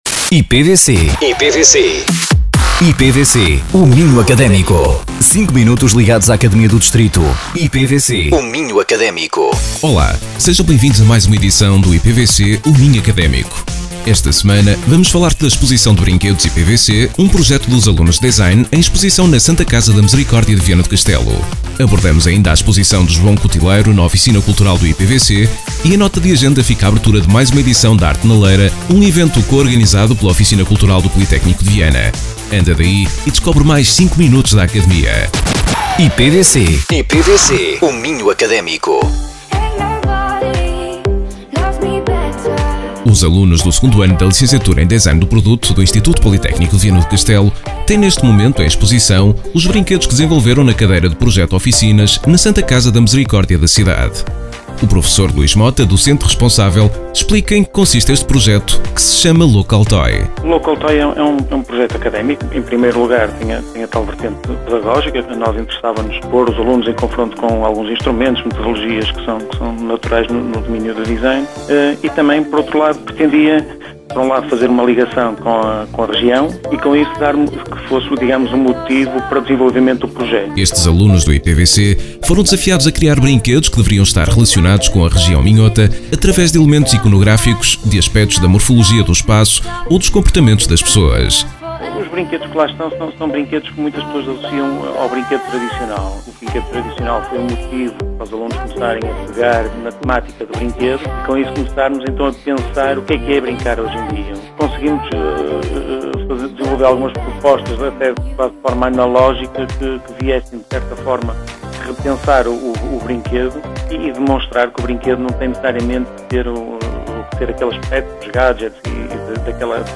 Entrevistados: